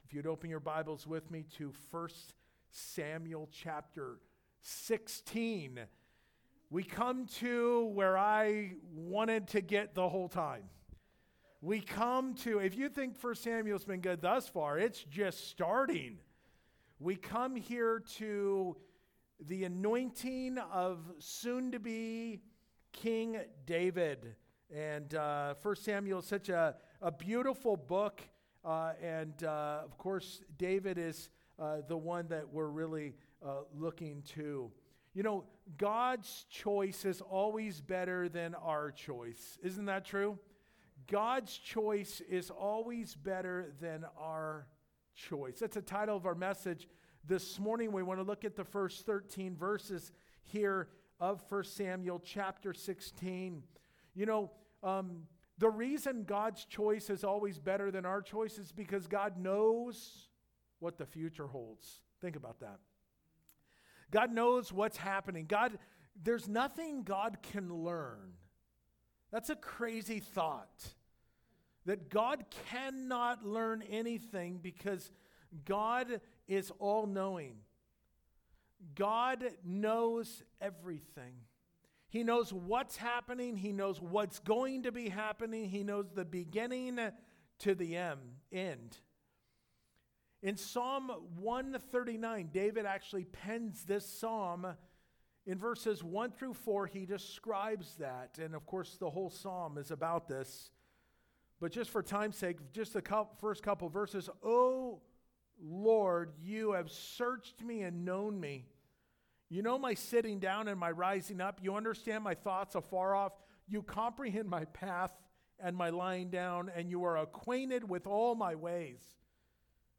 God’s+Choice+Is+Always+Better+Than+Our+Choice+2nd+Service.mp3